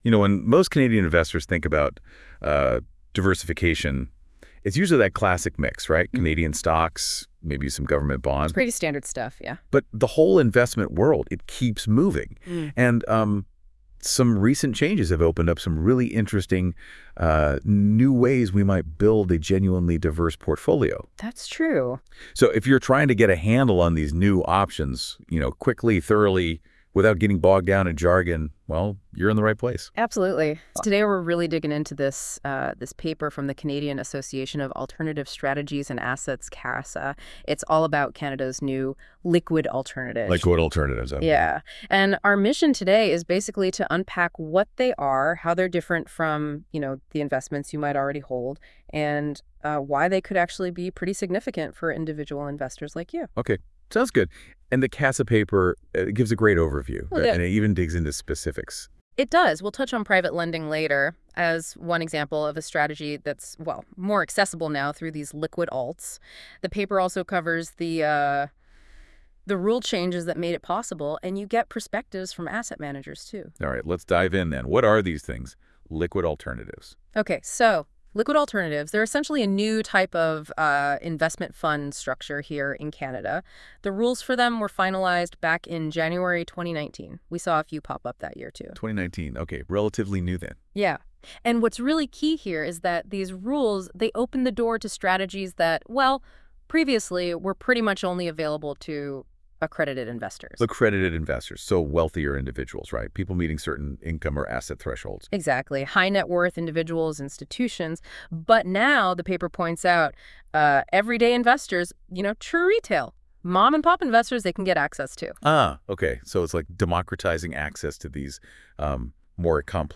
Hear our AI-enabled deep conversation (16mins) to gain an audio overview: click here.